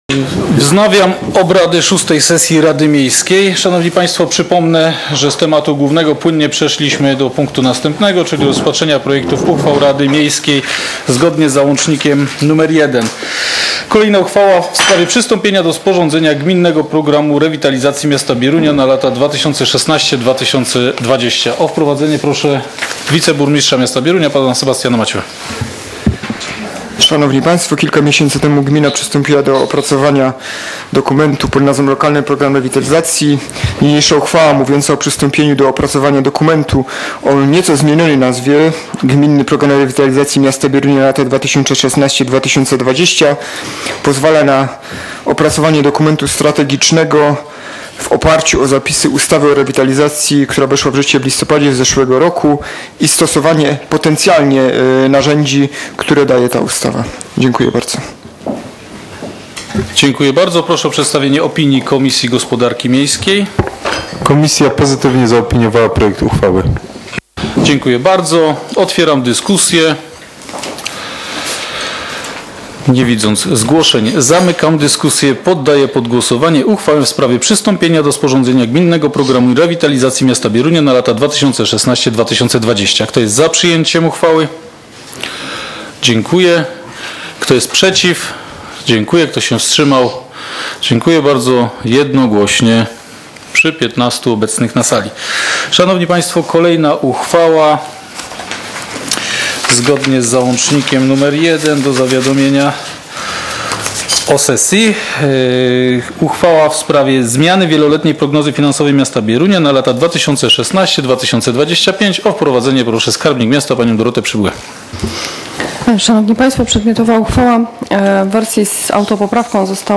z obrad VI sesji Rady Miejskiej w Bieruniu, która odbyła się w dniu 25.05.2016 r.